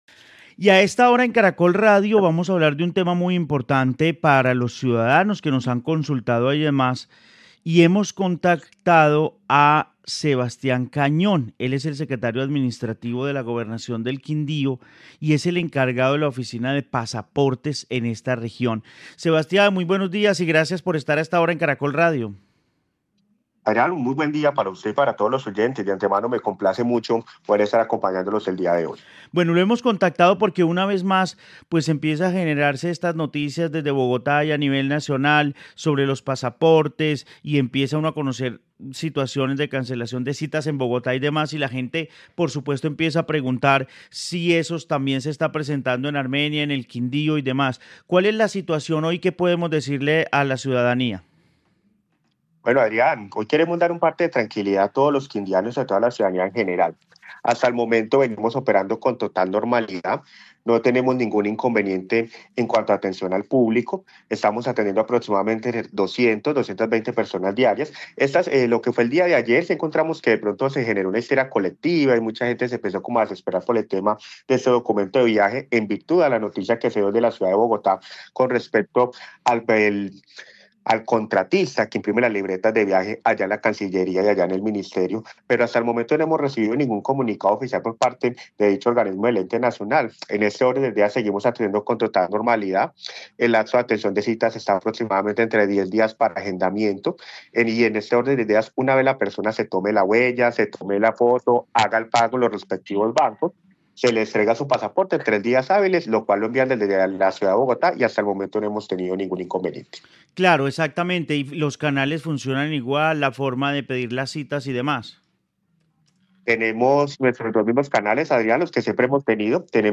Sebastián Cañon, director de oficina de pasaportes en Quindío
En el noticiero del mediodía de Caracol Radio Armenia hablamos con Sebastián Cañón, secretario administrativo de la gobernación del Quindío y director de la oficina de pasaportes que entregó un parte de tranquilidad sobre el trámite del documento en el departamento.